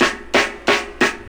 Snare (67).wav